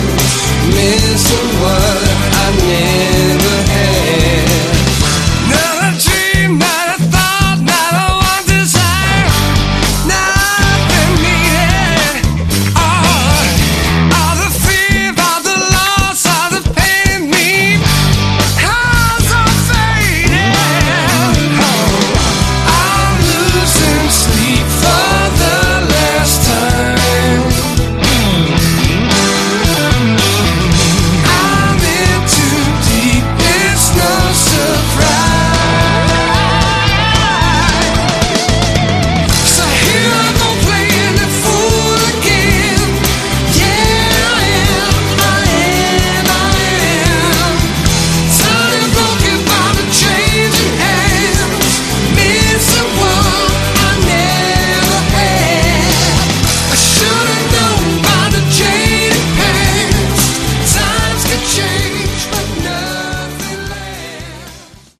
Category: Hard Rock
vocals, guitar, mandolin
keyboards
bass
drums, percussion
additional backing vocals